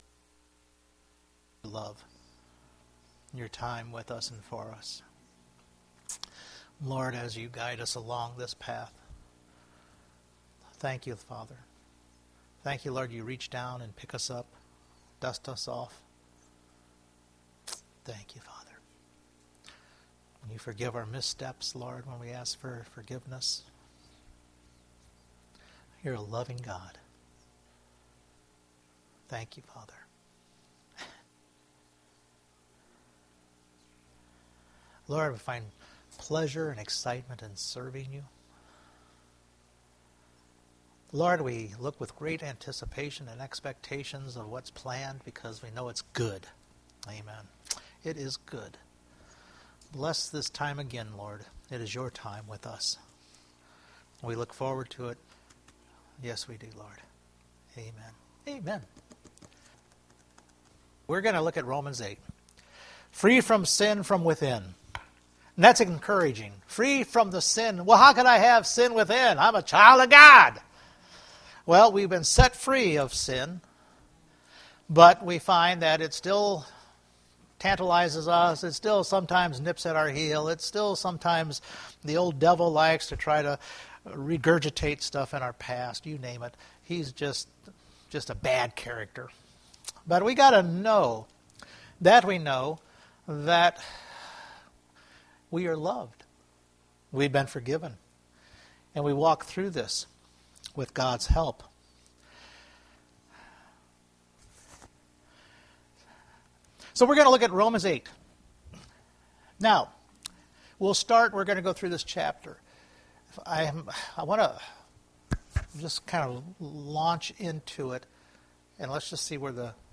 Romans Chapter 8 Service Type: Sunday Morning As a child of God we are free from sin that continues to try to indwell us day in and day out!